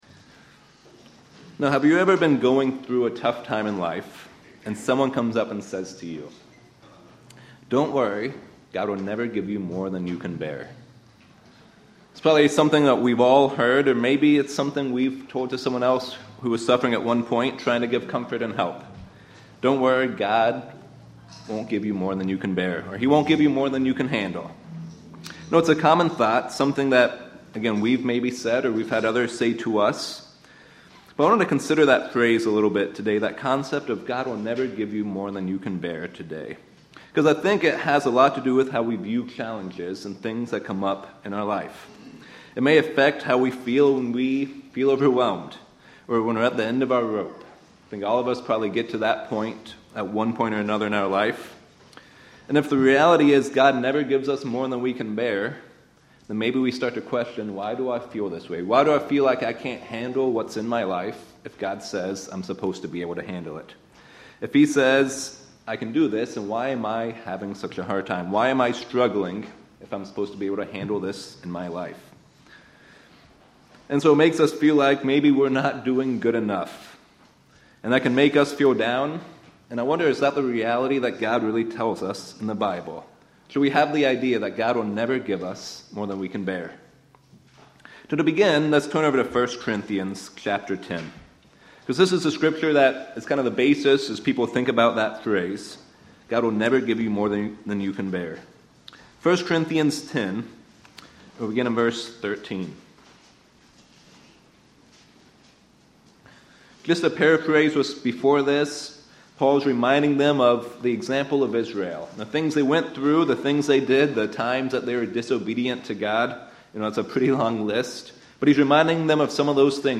This sermon dives into the reality of what God allows in our life.